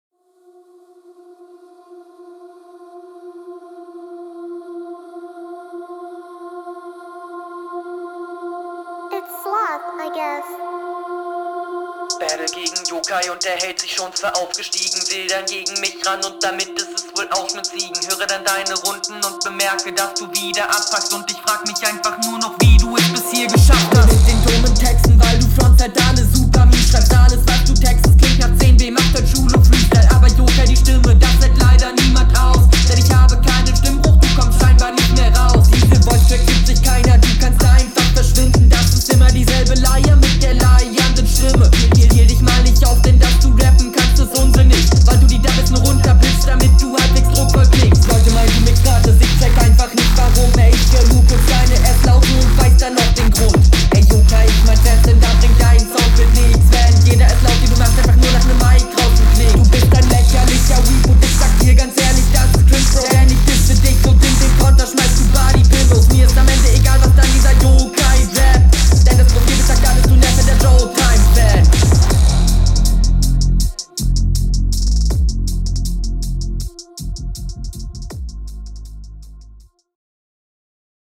Flow: Hier gefällt mir der Flow schon viel besser. Patterns sind ganz nice.
boah coool. mag die Kombi aus Rap und Lines und Beat voll.